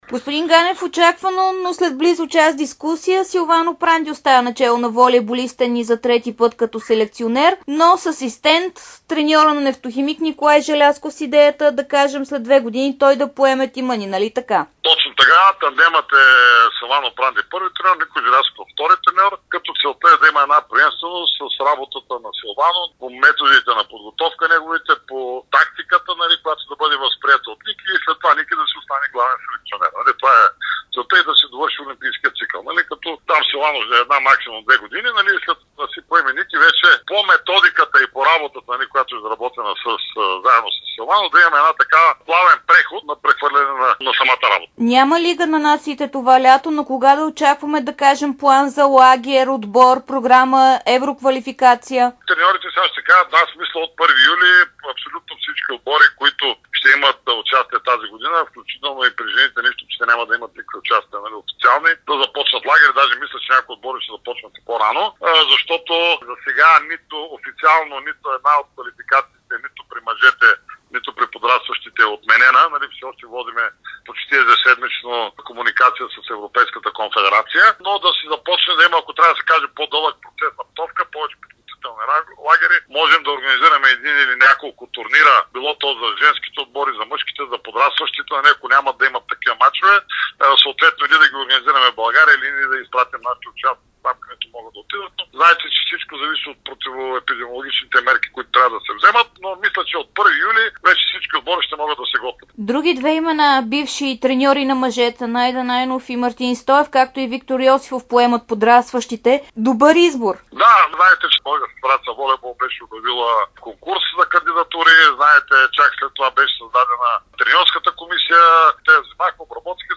Президентът на БФВ Любо Ганев даде специално интервю за Дарик радио и dsport, след като бяха избрани национални селекционери на България, в което разкри какви са целите отборите. Той заяви още, че Силвано Пранди ще остане да работи като главен треньор на мъжкото направление във волейбола и след приключване на ангажиментите му като национален селекционер.